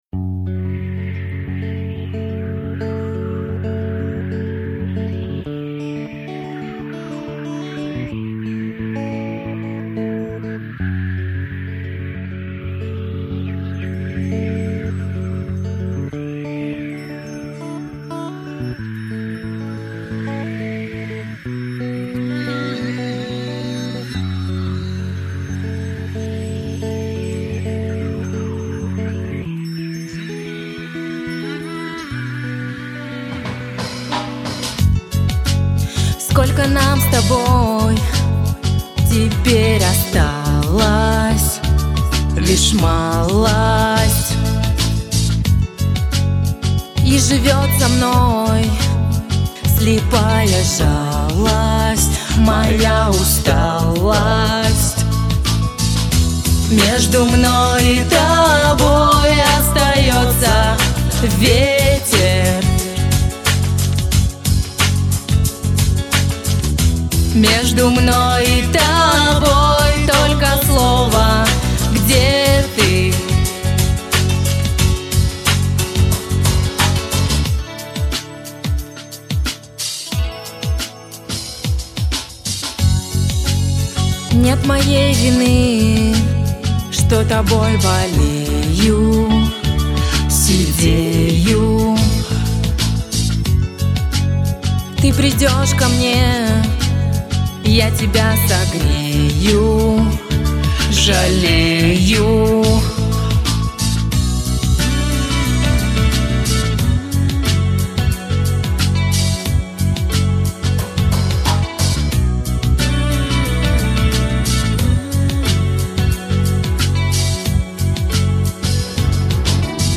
куплет взяла низковато